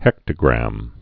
(hĕktə-grăm)